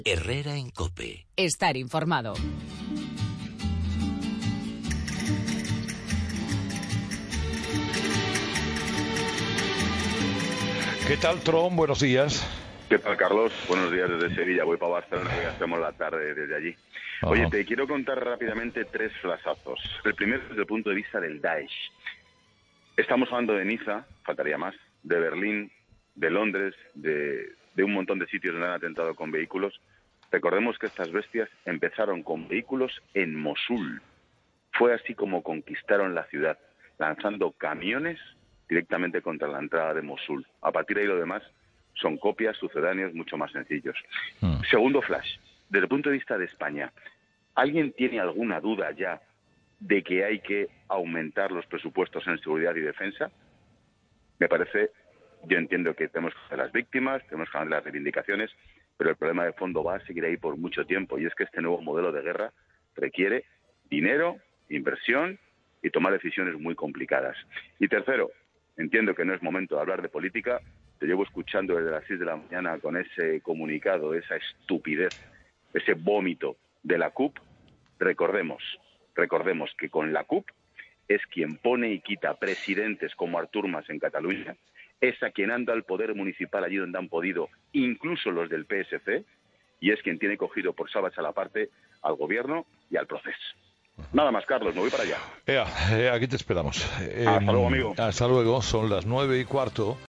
Ángel Expósito hace su paseíllo en 'Herrera en COPE'